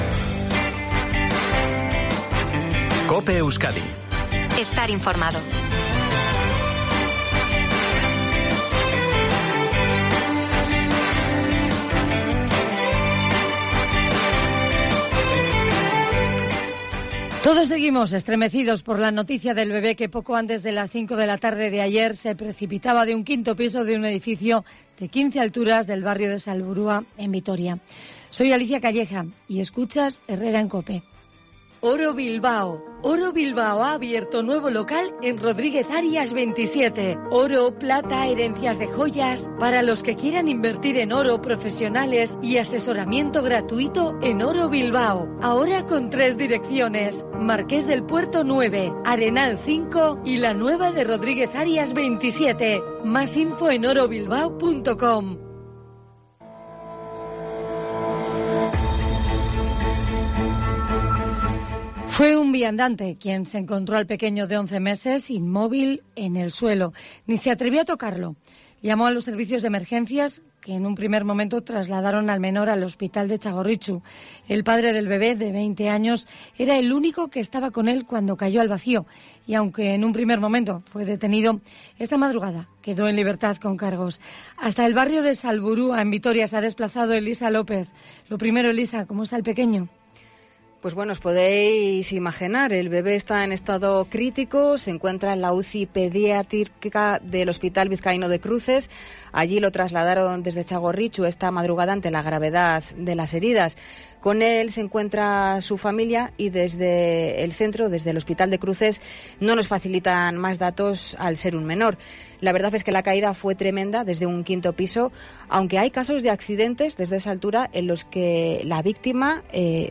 COPE Euskadi se desplaza a Salburua, donde cayó de un quinto piso un bebé de 11 meses